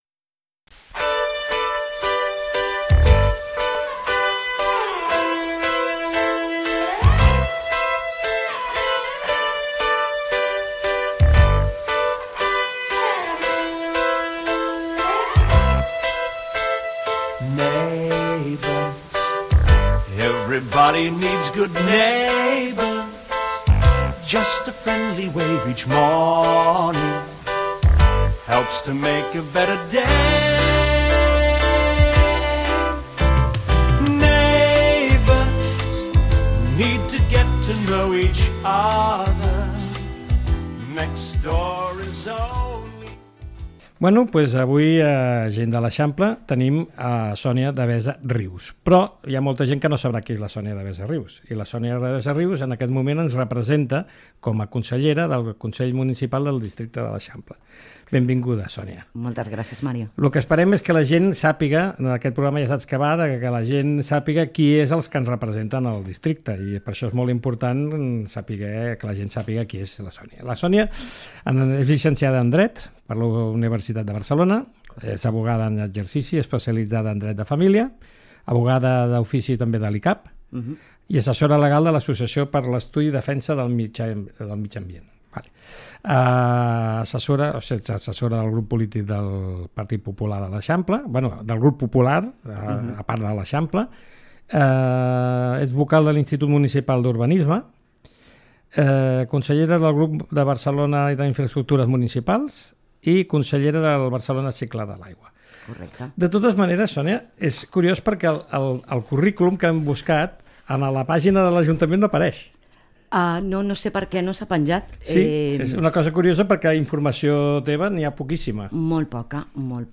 Gent-del-Eixample-entrevista-a-Sonia-Devesa-Rius-Consellera-del-districte-de-lEixample-per-el-PP-Barcelona.mp3